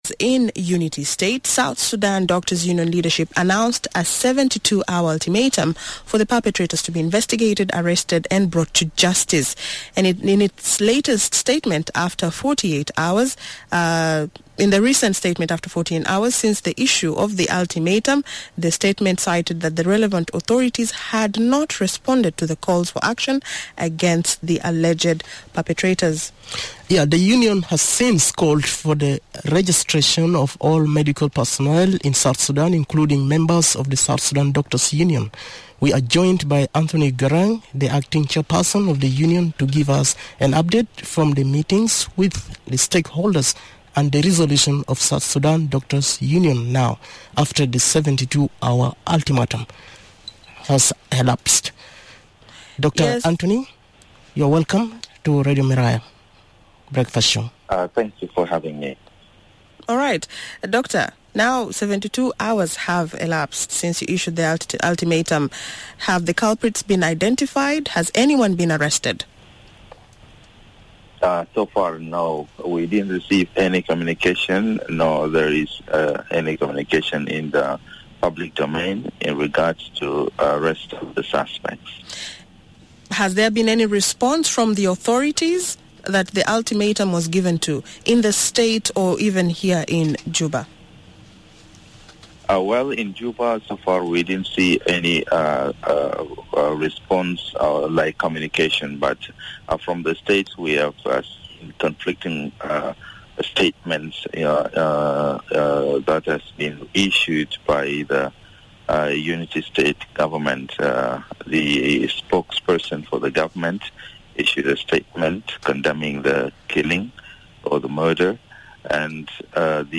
He spoke on the Miraya Breakfast Show